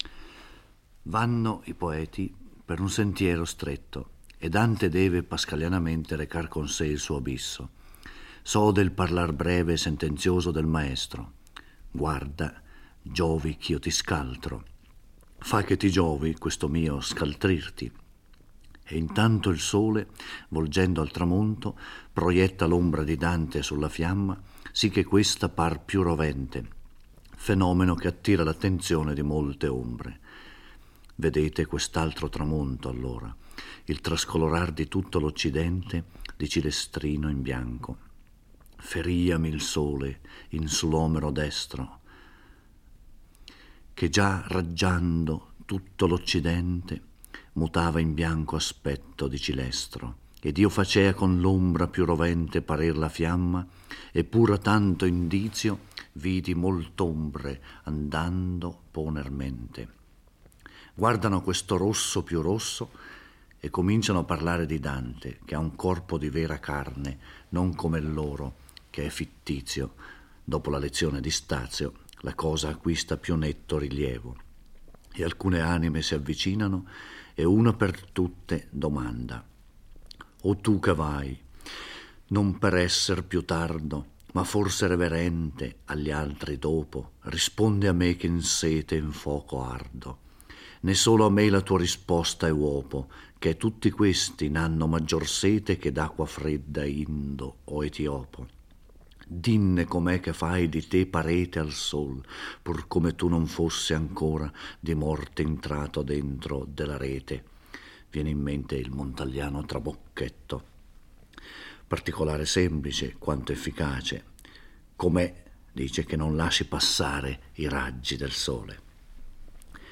Giorgio Orelli legge e commenta il XXVI canto del Purgatorio. Dante e Virgilio sono nella settima cornice, zona dedicata ai lussuriosi: obbligati a camminare tra le fiamme, ricordano il loro peccato piangendo ed elevando a Dio l'inno Summae Deus Clementïae, contenente un'invocazione contro la lussuria.